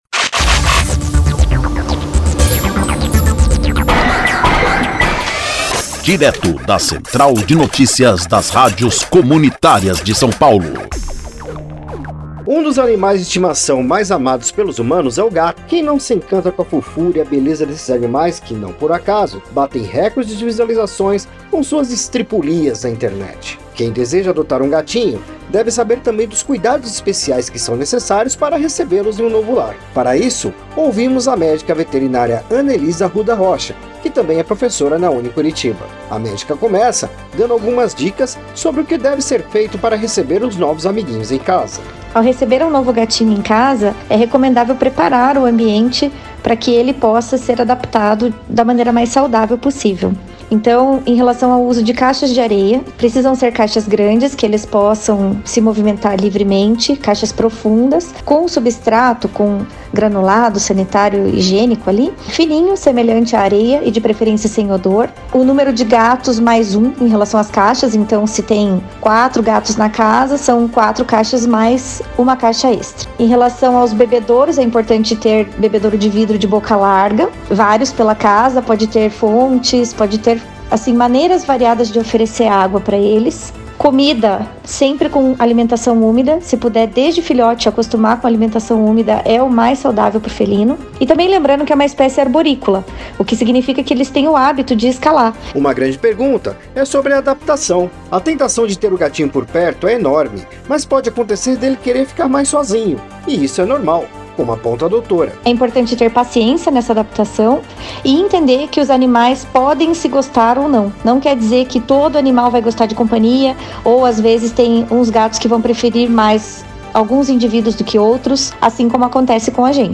Especialista fala dos cuidados ao receber um novo gato em casa